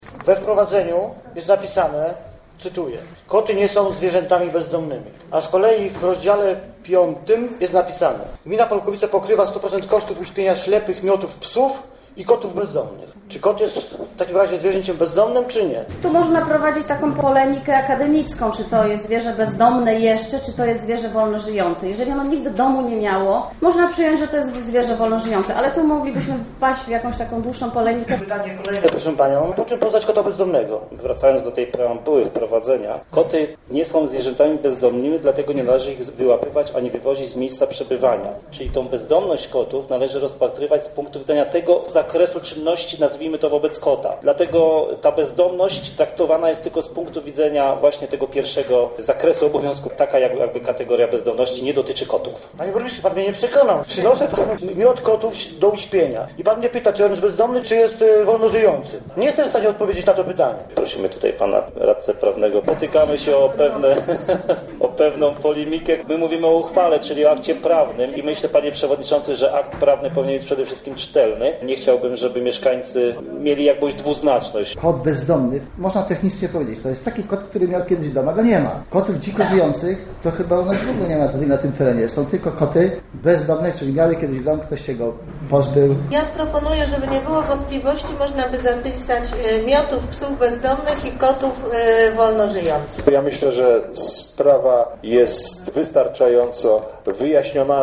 Dywagacjom na ten temat radni w Polkowicach poświęcili kilkanaście minut podczas ostatniej sesji miejskiej. Chodziło o dwuznaczność zapisów w programie opieki nad zwierzętami bezdomnymi oraz zapobiegania bezdomności zwierząt.
Ziarno wątpliwości zasiał wiceprzewodniczący rady Ireneusz Traczyk.